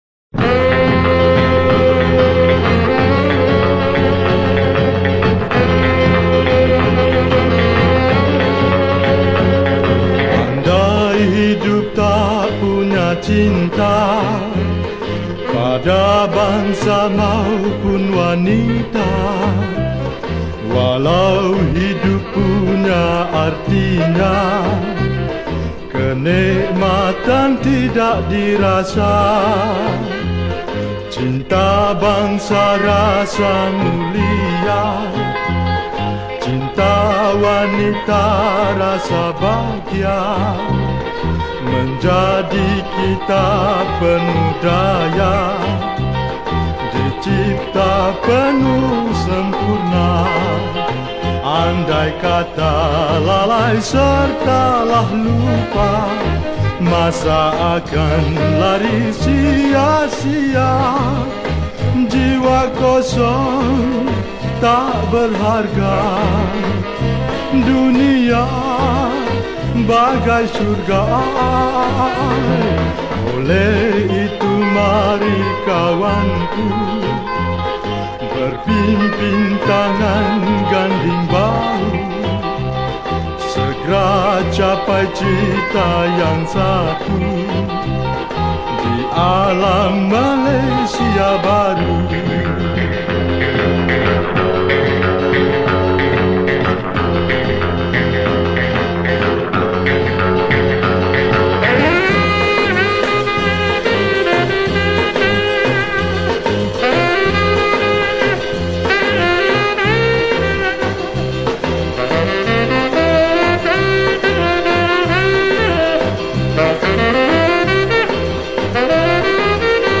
Patriotic Songs
Lagu Patriotik